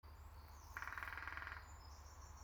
Pelēkā dzilna, Picus canus